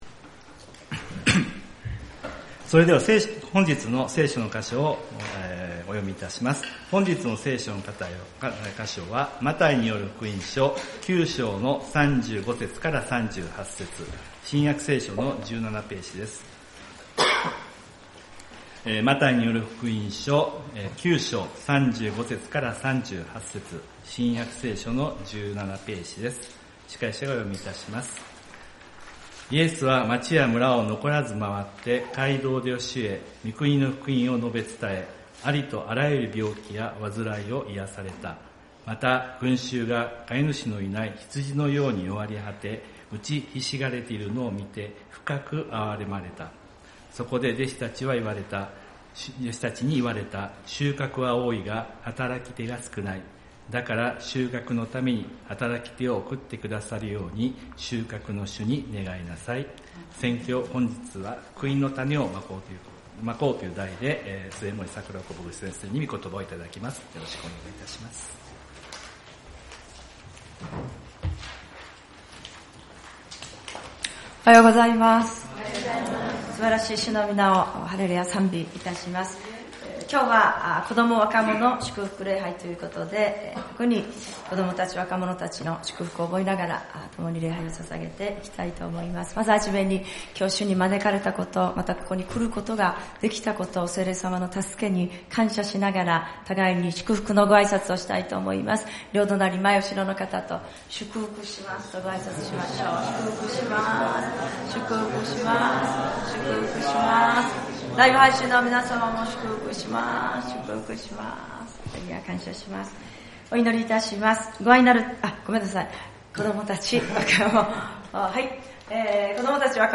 子ども若者祝福礼拝「福音の種を蒔こう」マタイによる福音書 9:35-38